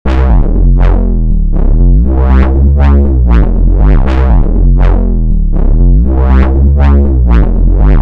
Haremos este sonido de bajo modulado flexible, aunque la técnica se extiende mucho más allá de los sonidos de bajo, en pads, riffs, batería y más.
Sonido de bajo modulado
modulation-loops-making-000.mp3